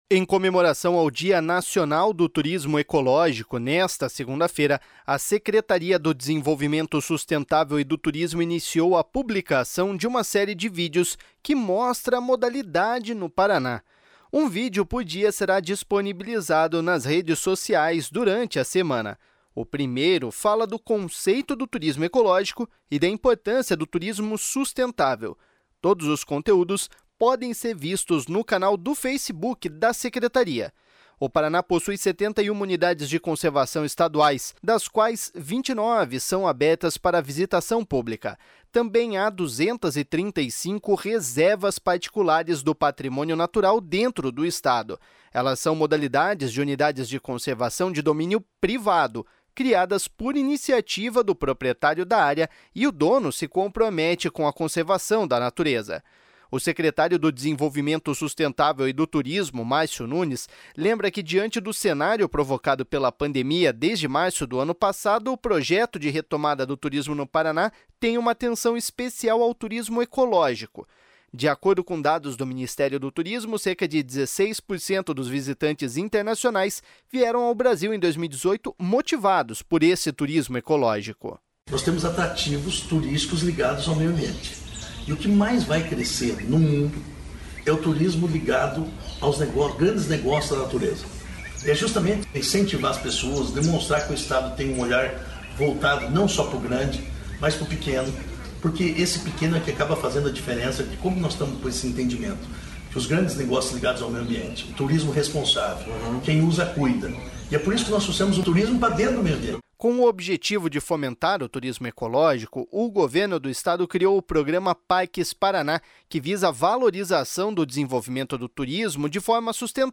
O secretário do Desenvolvimento Sustentável e do Turismo, Márcio Nunes, lembra que, diante do cenário provocado pela pandemia, desde março do ano passado o projeto de retomada do turismo no Paraná tem uma atenção especial ao turismo ecológico. De acordo com dados do Ministério do Turismo, cerca de 16% dos visitantes internacionais vieram ao Brasil em 2018 motivados pelo turismo ecológico.// SONORA MARCIO NUNES.//